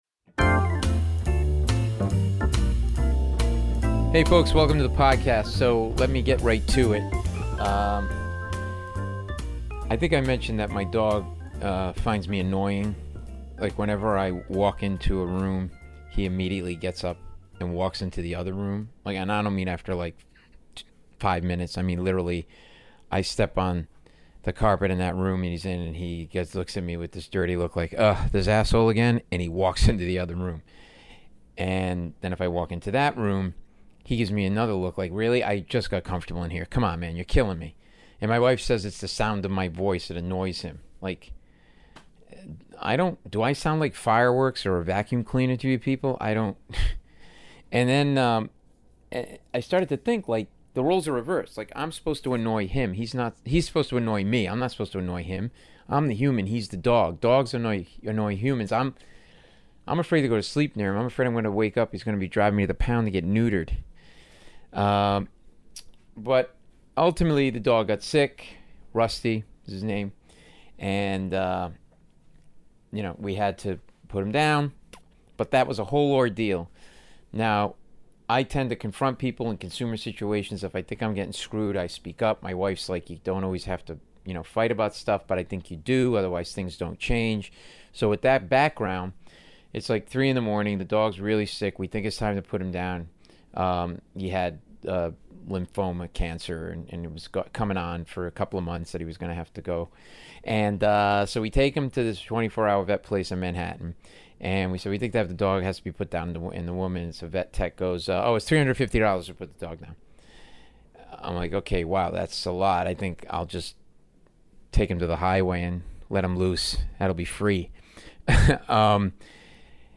Billy is really terrific in this episode, he's super honest - we really get to know him, like how he got started in comedy being a seater at a comedy club, his passion for stand-up, how he got the lead role in "Mike and Molly" with Melissa McCarthy (amazing story), how important family is, his current role playing Colonel Tom Parker (Elvis' Manager) and more. This is a a fun, complete interview that covers so much ground.